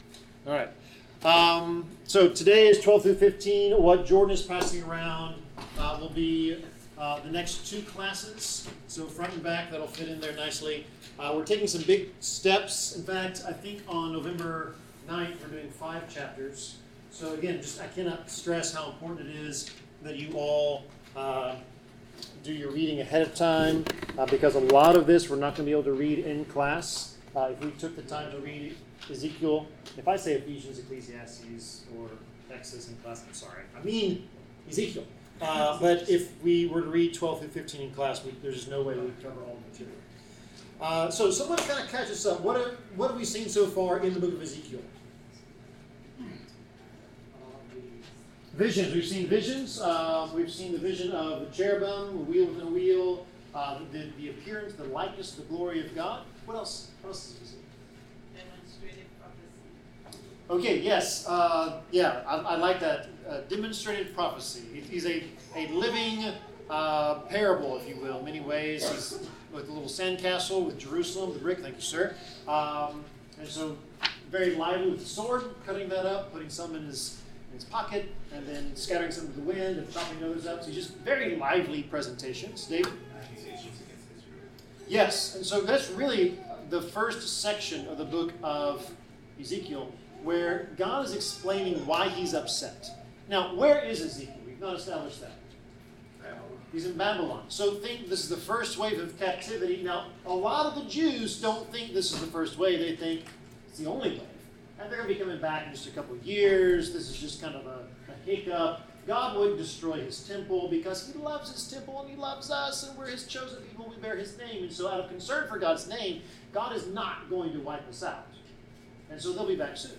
Bible class: Ezekiel 12-15
Service Type: Bible Class